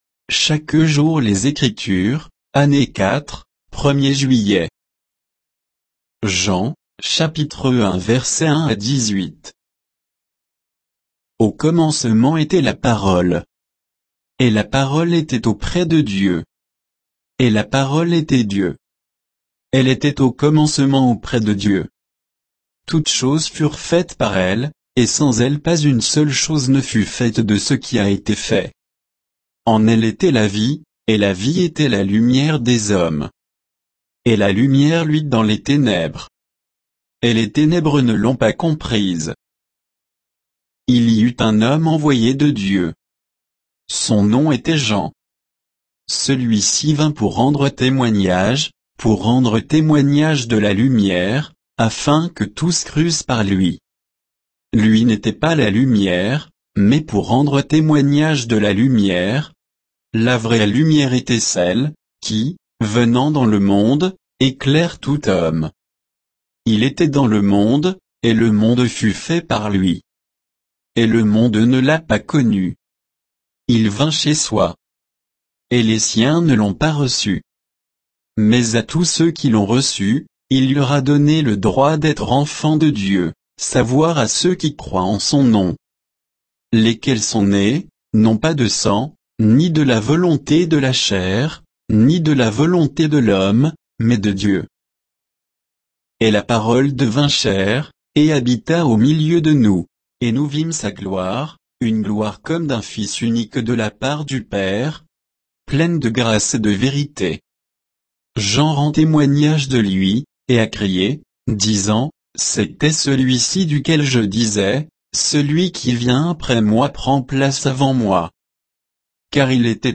Méditation quoditienne de Chaque jour les Écritures sur Jean 1